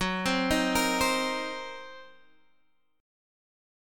F#6b5 chord